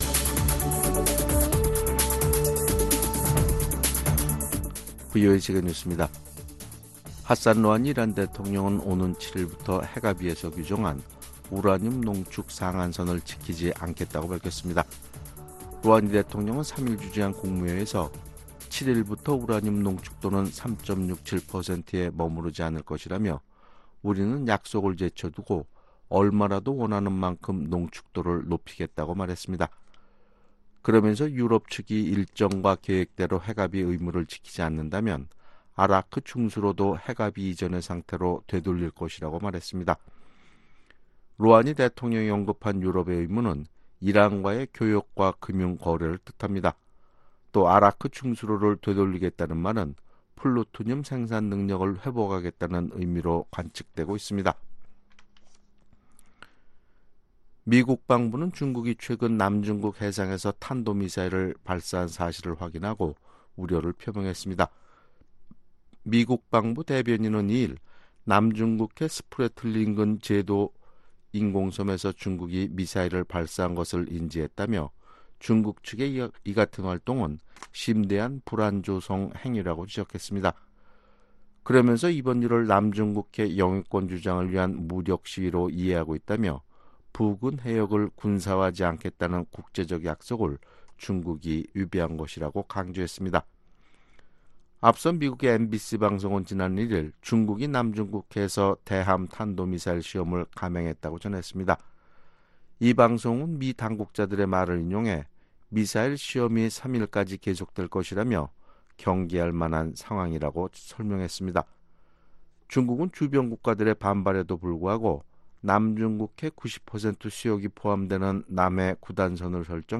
VOA 한국어 아침 뉴스 프로그램 '워싱턴 뉴스 광장' 2019년 7월 3일 방송입니다. 미-북 비핵화 협상과 관련해 한국정부의 입장은 완전한 비핵화라고 한국 외교장관이 밝혔습니다.